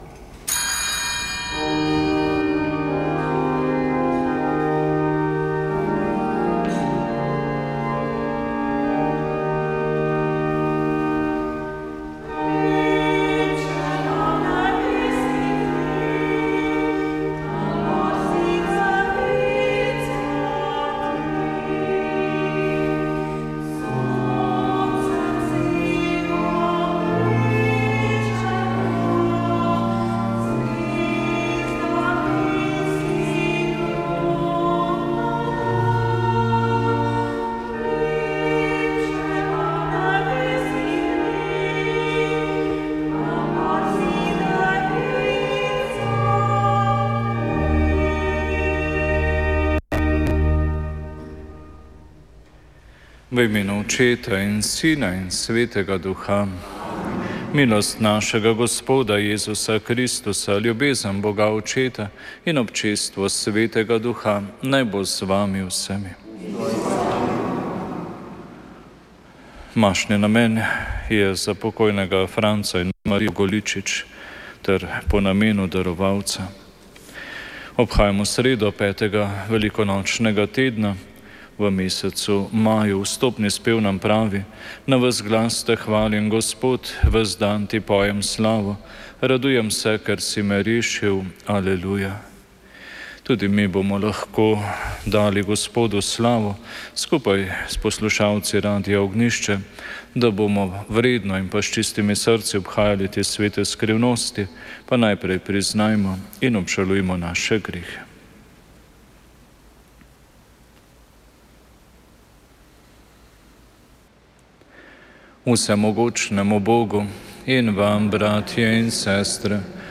Sveta maša
Sveta maša ob 6. dnevu radijskega misijona